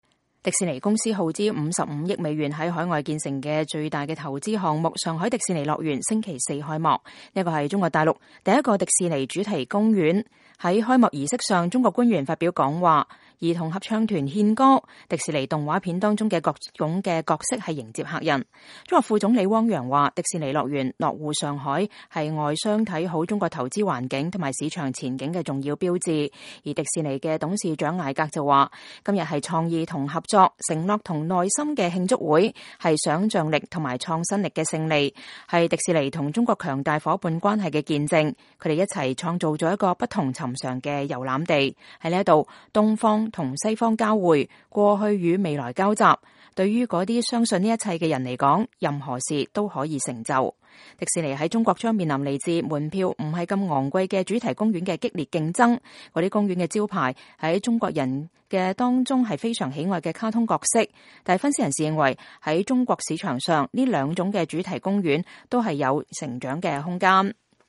在開幕儀式上，中國官員發表講話，中國兒童合唱團獻歌，迪士尼動畫片中的各種角色迎接客人。
中國副總理汪洋說：“迪士尼樂園落戶上海，是外商看好中國投資環境和市場前景的重要標誌。”